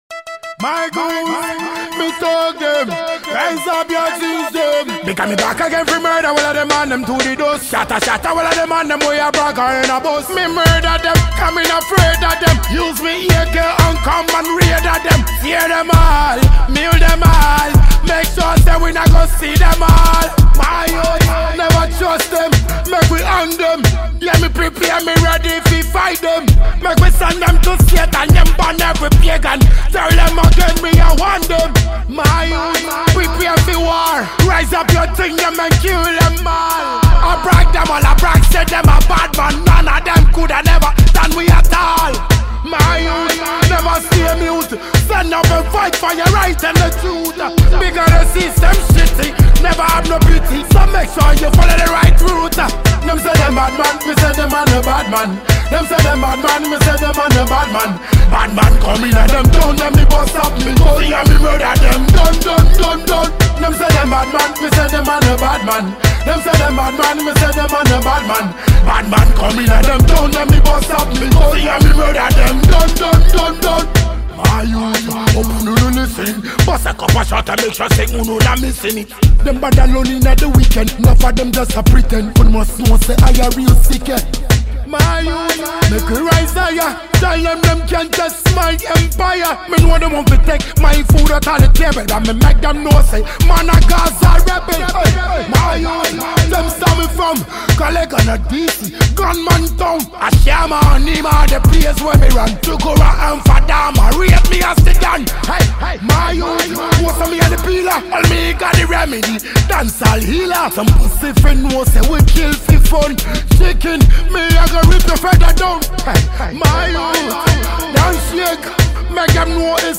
Ghana Music
Ghanaian reggae dancehall artist